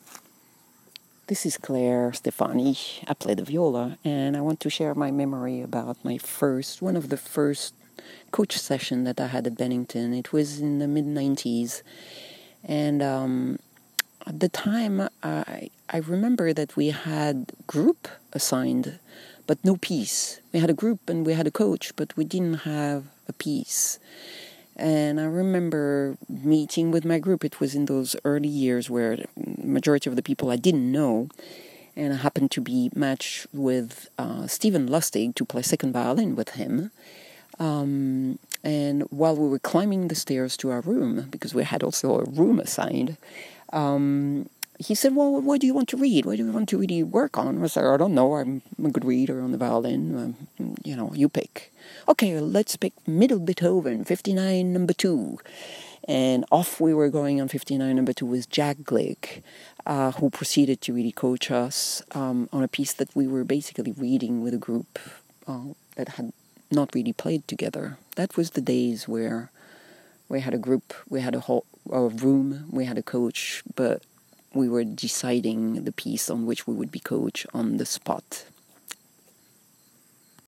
CMC Stories was initiative to collect oral histories from the CMC community.